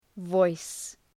Προφορά
{vɔıs}